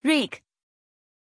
Pronuncia di Èric
pronunciation-èric-zh.mp3